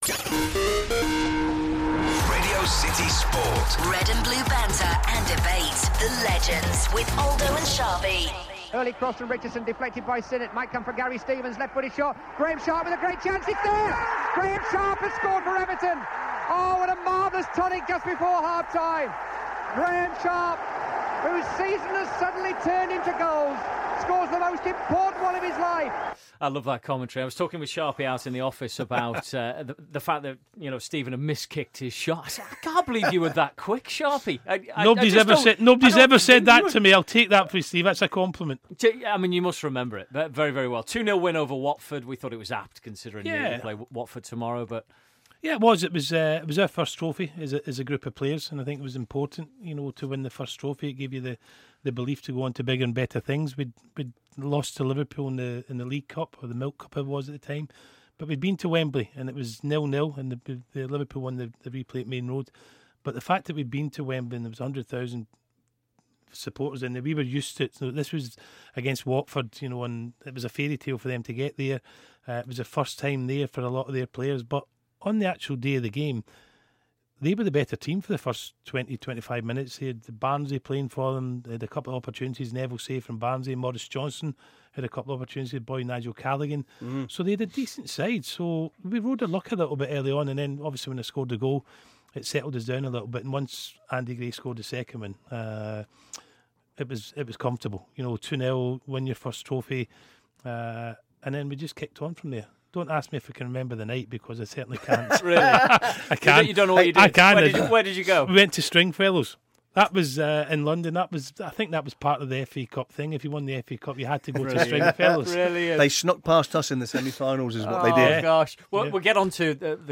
The Legends Phone-In / Graeme Sharp / Mark Wright (Callers) - Friday 7th August 2015